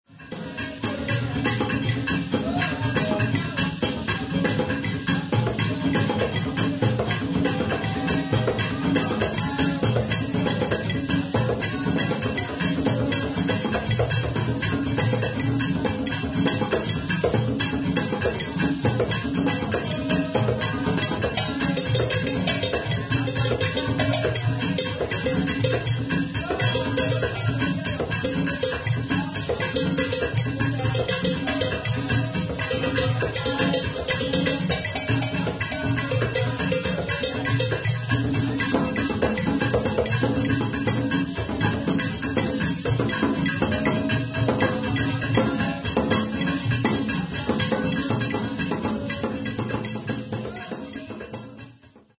The prempensua is a lamellaphone used by the Akan from Ghana, where it replaces the traditional drums such as the atumpan, apentemma and the petia. Three lamellae (in some cases five), each with a different sound, are fitted onto a large rectangular wooden box that serves as the resonator and amplifies the sounds produced by the lamellae, which play ostinato figures.
The instrument has a rounded bass tone. The timbre can be enriched by attaching small metal plates to the instrument. When one of the lamellae is plucked, these metal plates vibrate, thus changing the timbre. The musician sits on the prenpensua as he plays the instrument with the fingers of both hands.